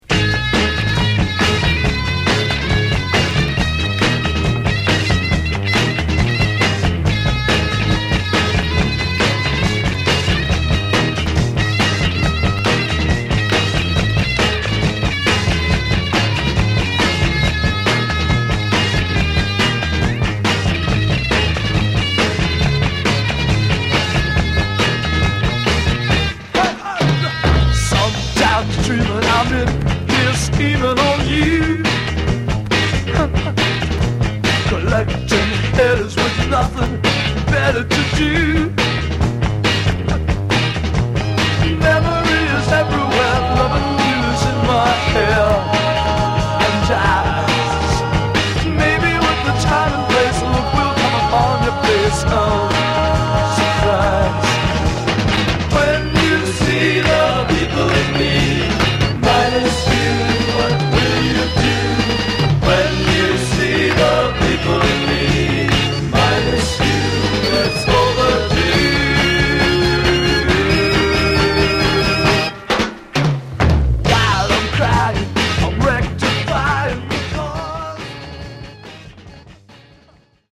Genre: Garage/Psych
The A side is a top-notch psych-rock performance...